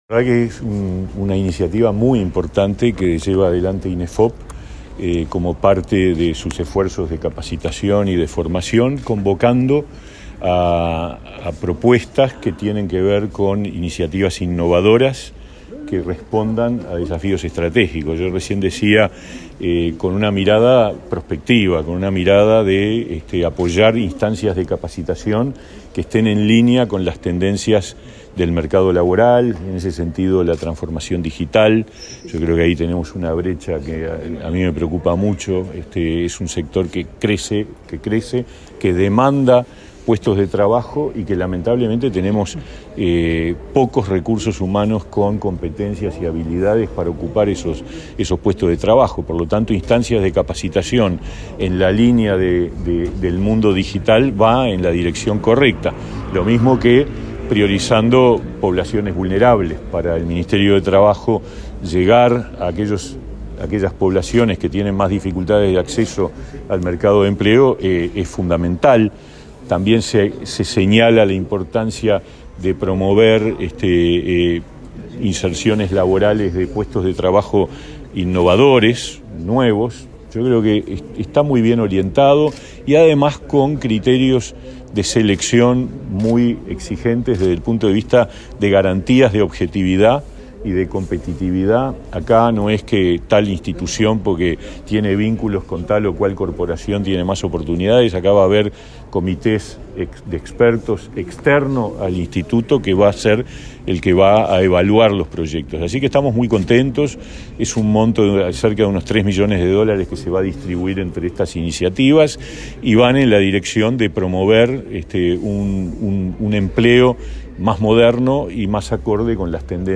Declaraciones del ministro de Trabajo, Pablo Mieres
Declaraciones del ministro de Trabajo, Pablo Mieres 20/07/2022 Compartir Facebook X Copiar enlace WhatsApp LinkedIn El Instituto Nacional de Empleo y Formación Profesional (Inefop) lanzó la segunda edición de la iniciativa Respuestas Innovadoras a Desafíos Estratégicos (RIDE). El ministro de Trabajo, Pablo Mieres, dialogó con la prensa y destacó la trascendencia de la propuesta.